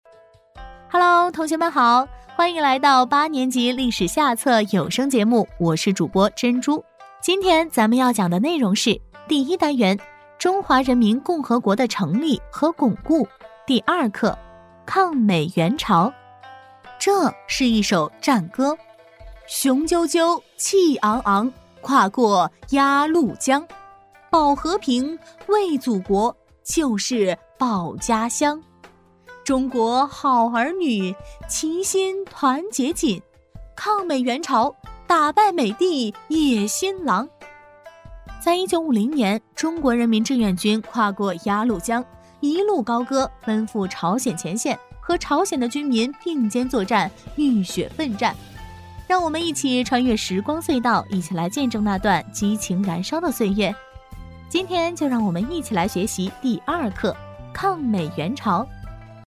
女C3-有声课件《作业帮- 部编八下历史》
女C3-有声课件《作业帮- 部编八下历史》.mp3